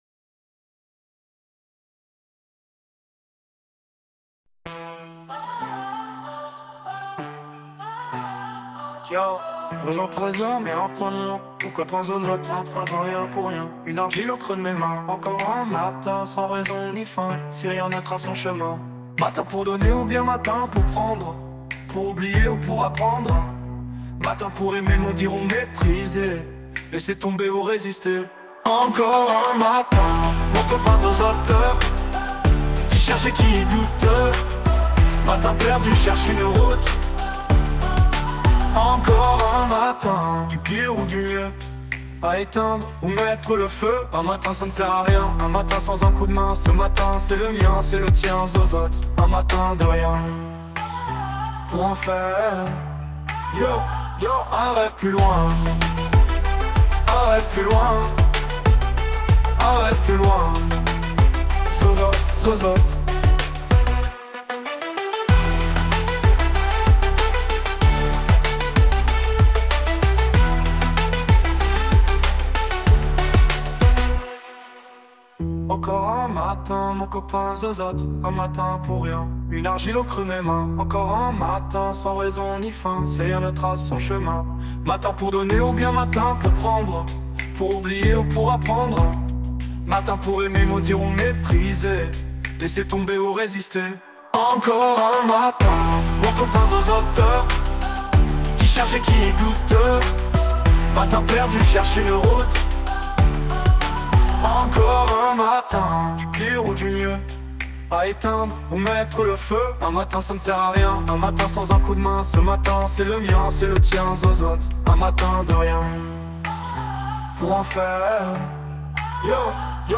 façon rap